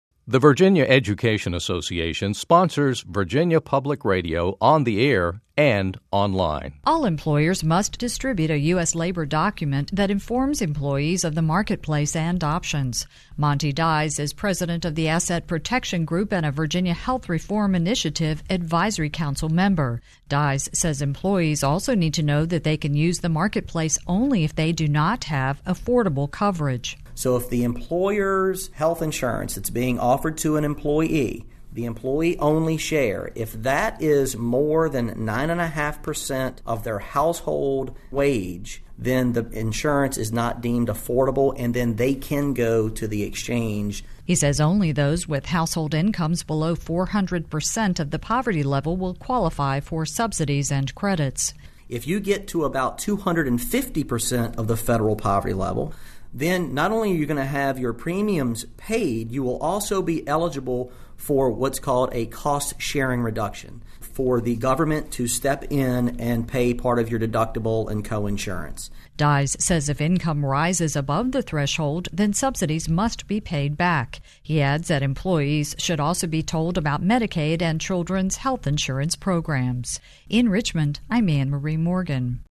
This entry was posted on October 1, 2013, 4:24 pm and is filed under Daily Capitol News Updates.